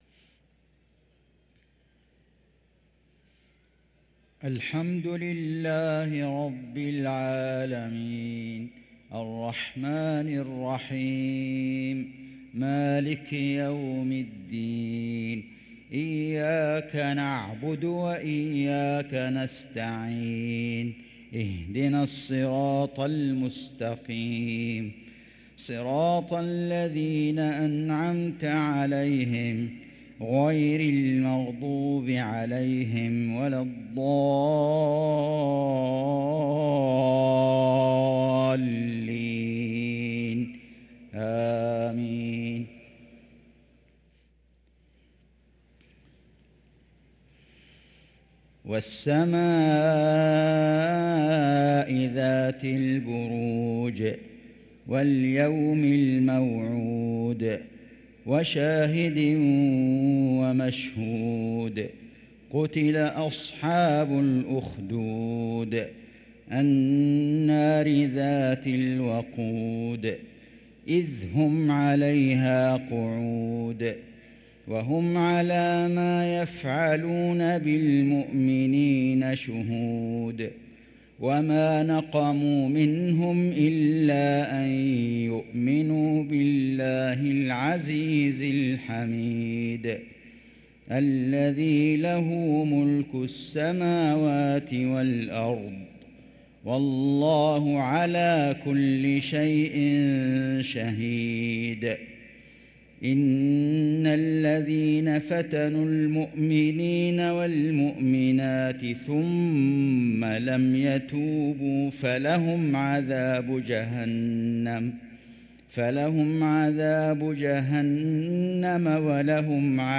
صلاة العشاء للقارئ فيصل غزاوي 28 ربيع الأول 1445 هـ